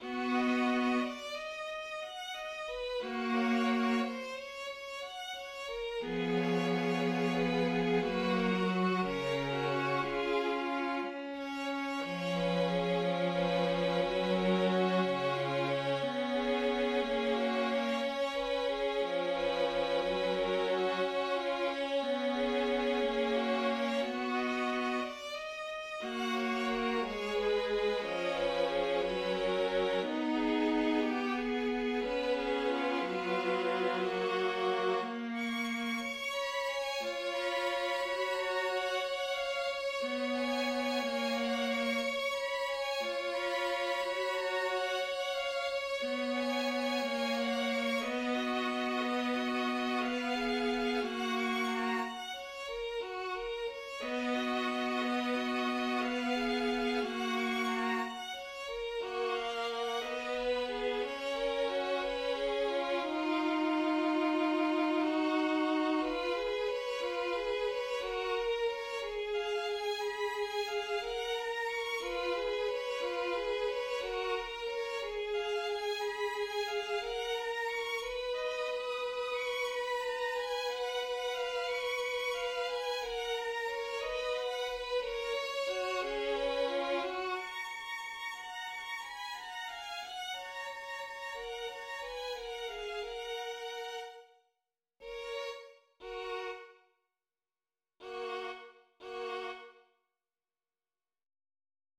Presto - Adagio
, en la majeur, puis en fa # majeur à partir de la mesure 68
Dans l'Adagio, les instruments s'arrêtent progressivement de jouer :
• restent les violons I et II qui jouent (avec sourdines) jusqu'à la mesure 107.
Fin de l'Adagio (mesures 74 à 107, avec l'arrêt progressif du Vc, des V3 et V4 puis de l'A) :